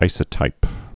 (īsə-tīp)